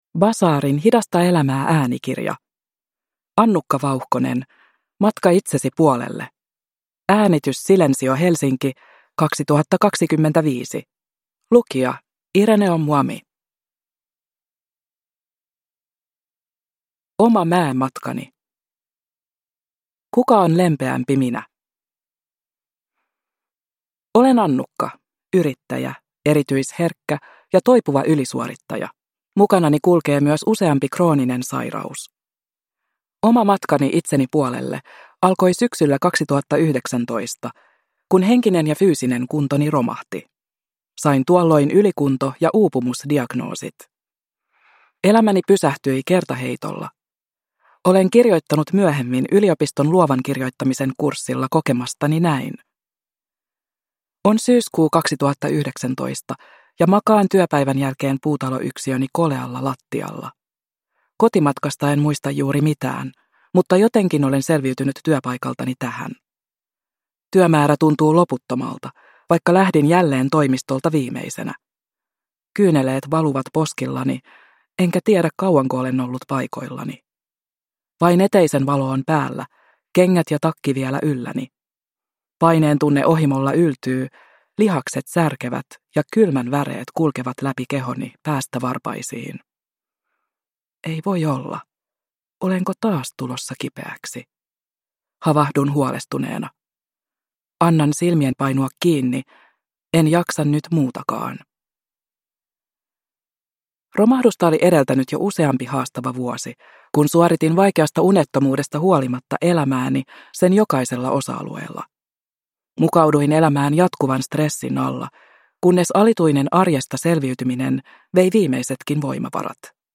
Matka itsesi puolelle – Ljudbok